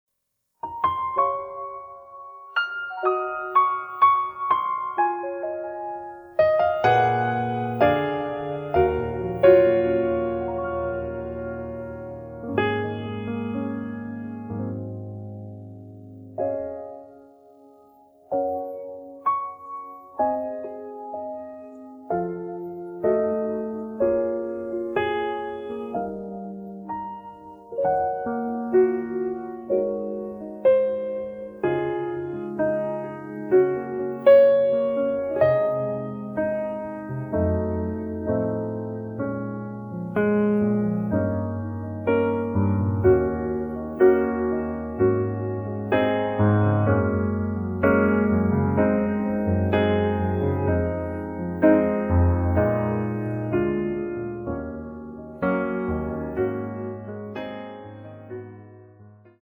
음정 원키 3:38
장르 가요 구분 Voice Cut
Voice Cut MR은 원곡에서 메인보컬만 제거한 버전입니다.